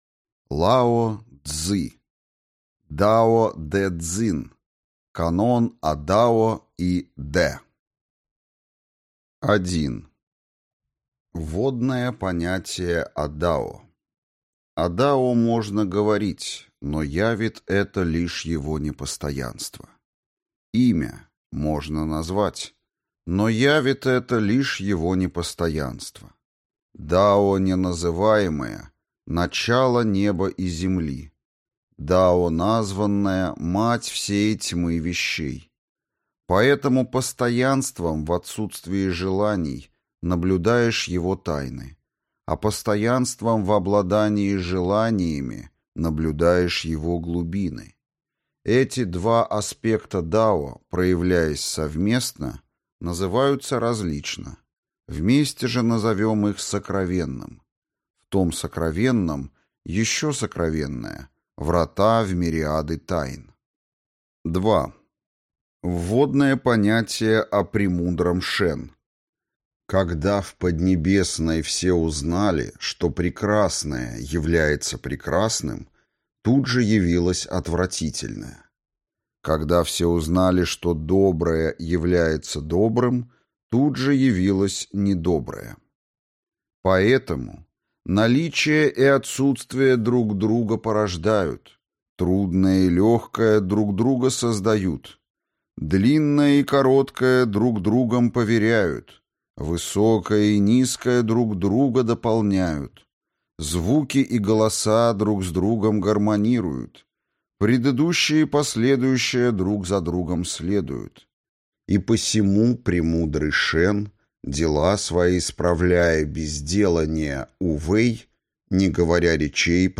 Аудиокнига Дао дэ цзин. Канон о Дао и дэ | Библиотека аудиокниг